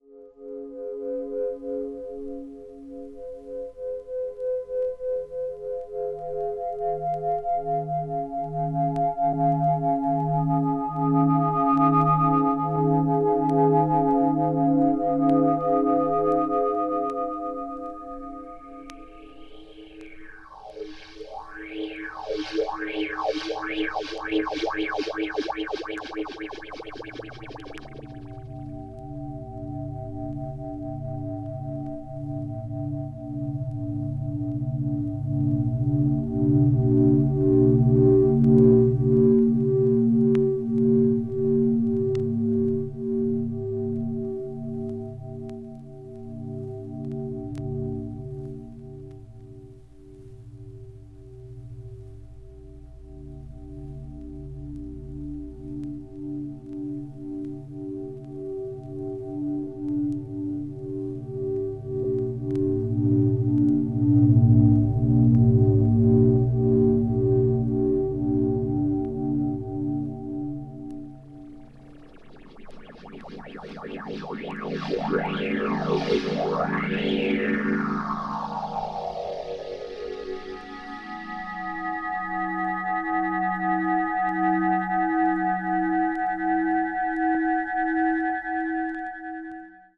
some slightly surface noises.
cosmic electronic sounds from electronic oscillators
a floating soundscape of pulses, drones and white noise
Enjoy the world of pure electronic sound.